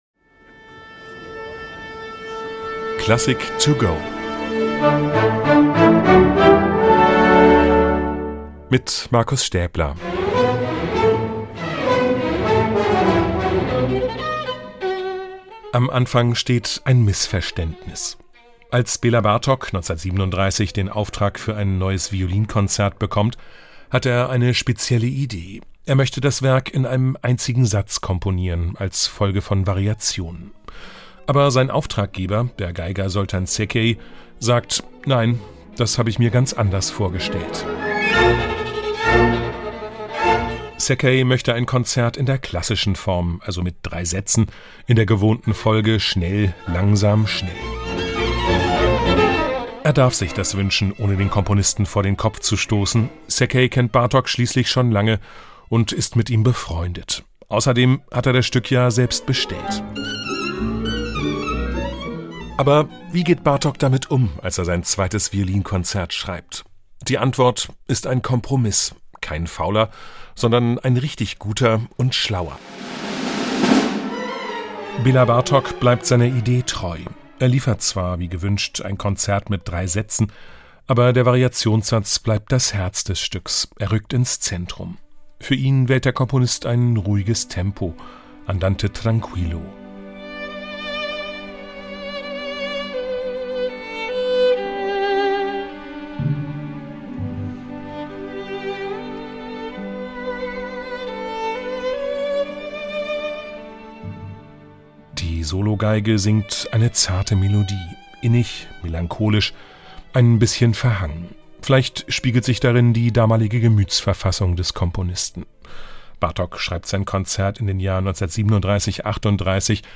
Kurzeinführung für unterwegs